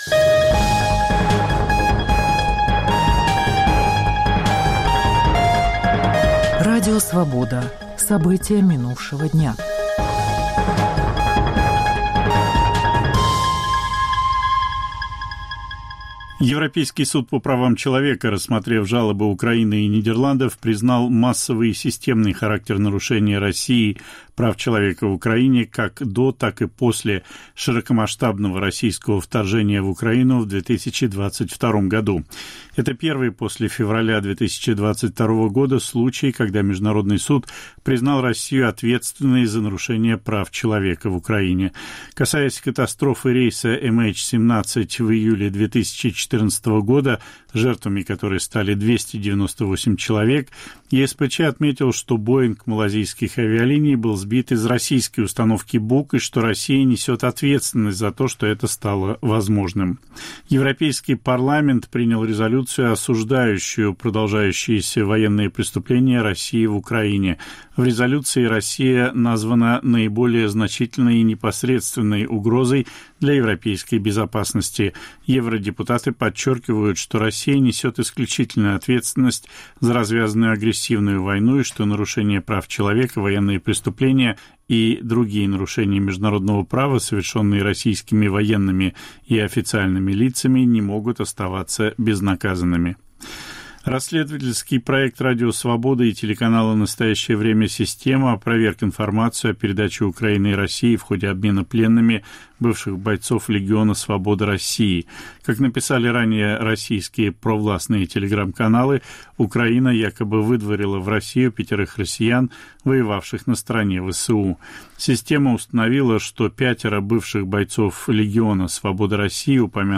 Новости Радио Свобода: итоговый выпуск